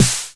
Index of /90_sSampleCDs/Masterbits - Soniq Elements/SNARES TR9+8/SNR909PITCH0